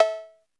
DB - Percussion (22).wav